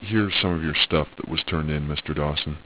• This version contains two additional voice clips: